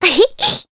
Cleo-Vox_Happy1_jp.wav